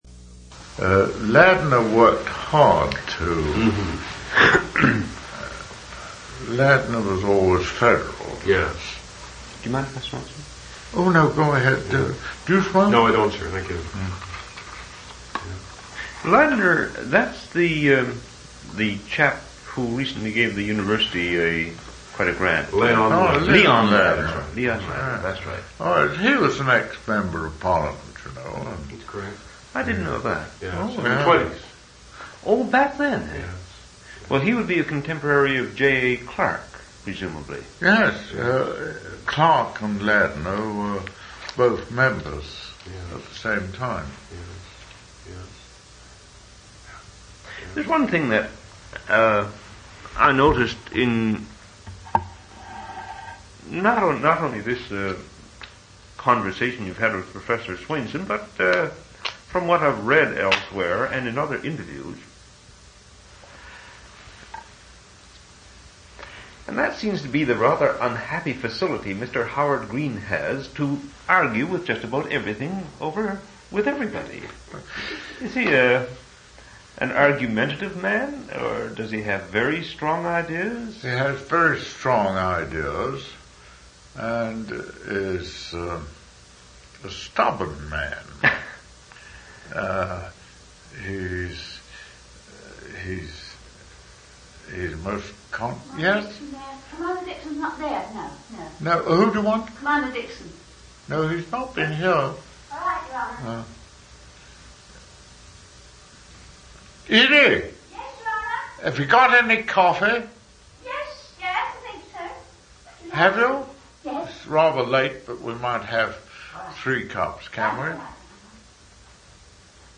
An interview/narrative of George Randolph Pearkes's political recollections. Major-General Pearkes, V.C., C.C., C.B., D.S.O., M.C., C.D. was a Member of Parliament from 1945-1960.
One original sound tape reel (ca. 77 min.) : 1 7/8 ips, 2 track, mono. ; 1 sound cassette copy : standard, mono. in Special Collections.